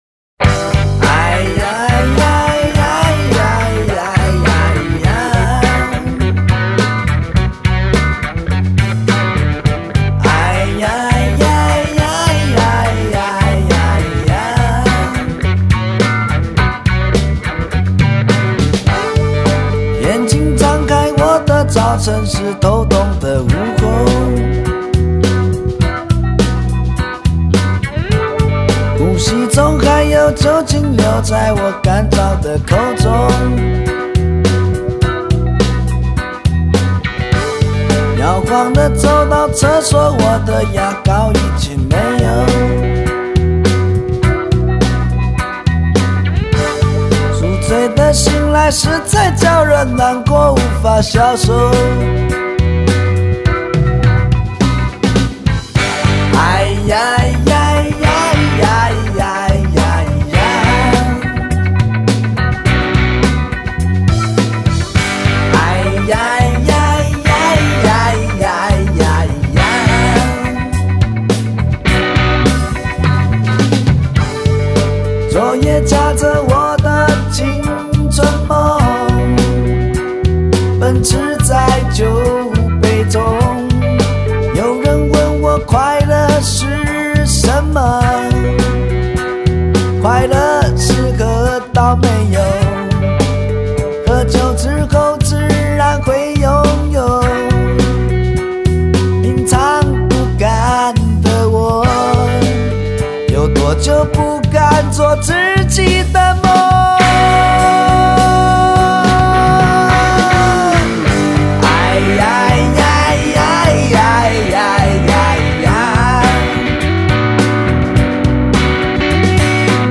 歌手类别：台湾乐队